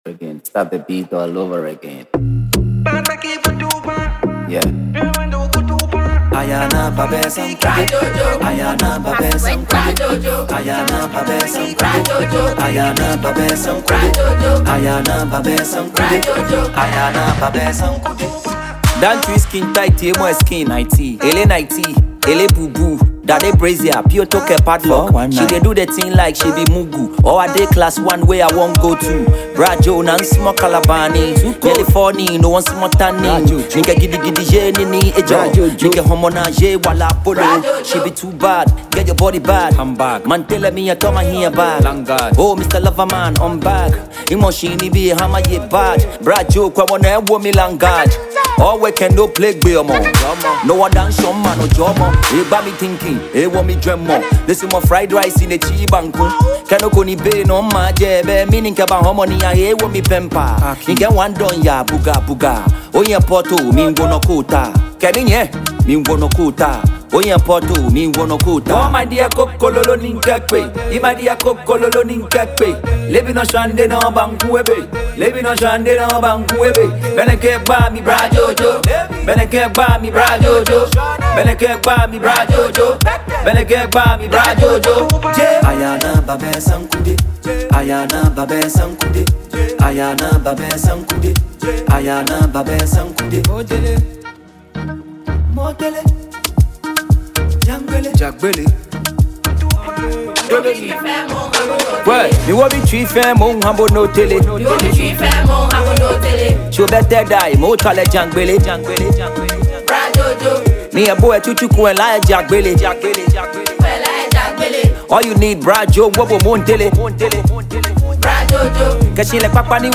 Genre: Hip-Hop / Conscious Rap